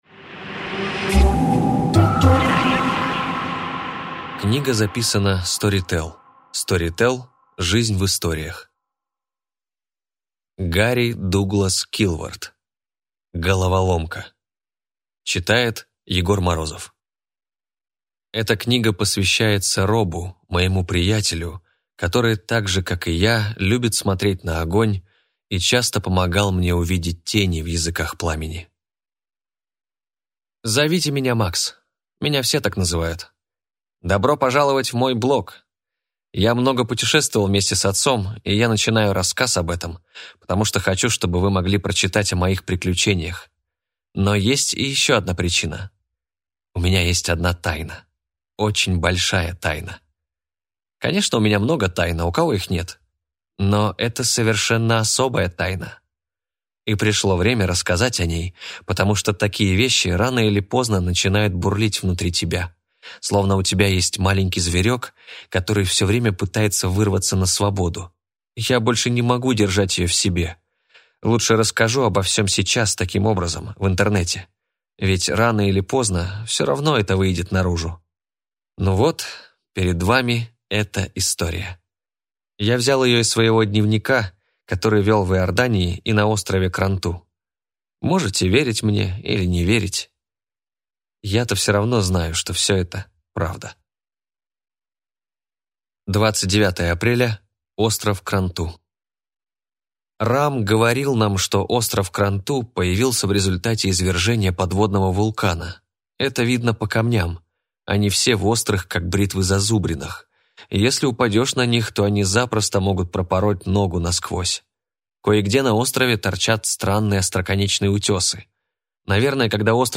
Аудиокнига Головоломка | Библиотека аудиокниг